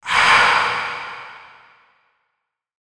ahh-sound